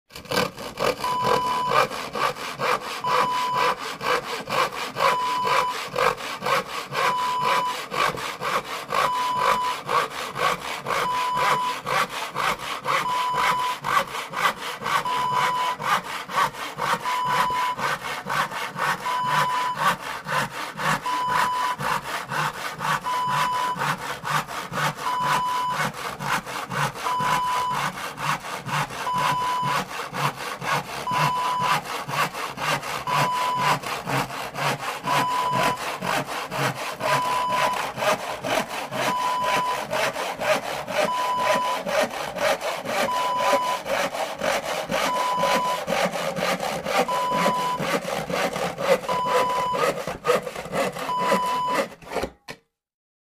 Hand Saw Wav Sound Effect #2
Description: The sound of a handsaw cutting wood
Properties: 48.000 kHz 16-bit Stereo
A beep sound is embedded in the audio preview file but it is not present in the high resolution downloadable wav file.
Tags: saw
handsaw-preview-2.mp3